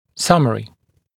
[‘sʌmərɪ][‘самэри]краткое изложение, резюме